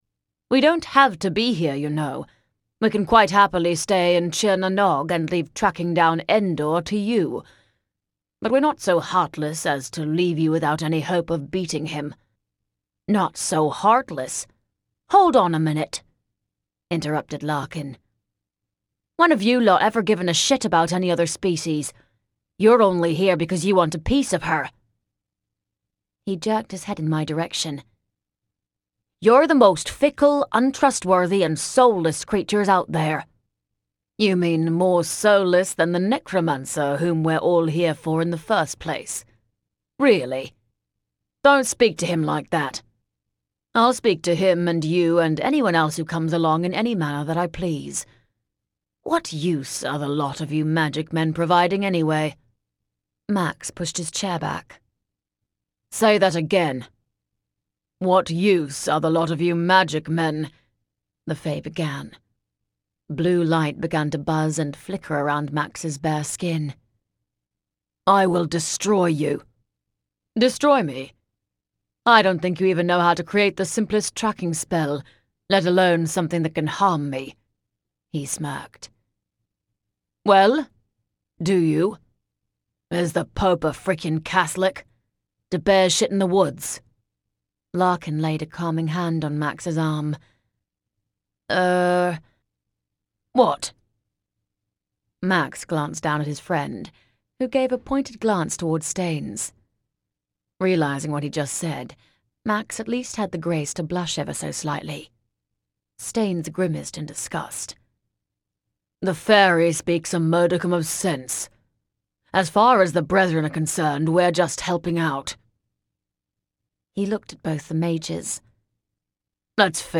BD5_Bloodlust_Tantor_Sample.mp3